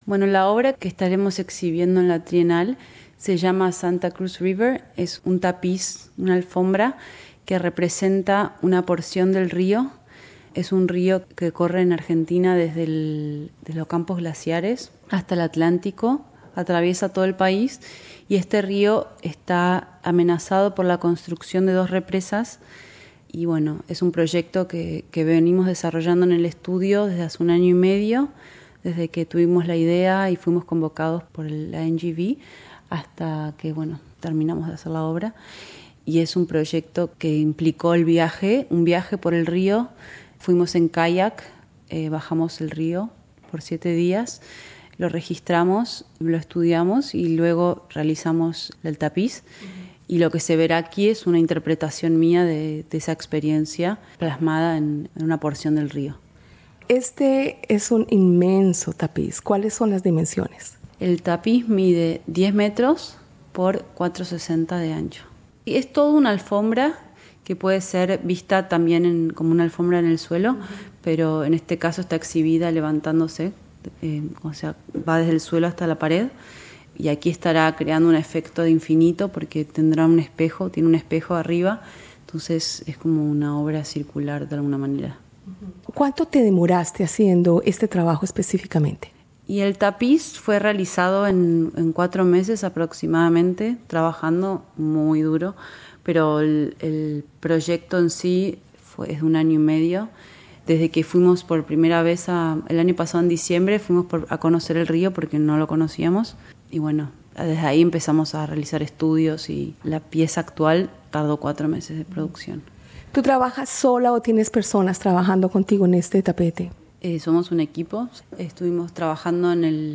En la exhibición de la NGV, que va hasta el 15 de Abril próximo, participan más de un centenar de artistas de 32 países. Escucha la entrevista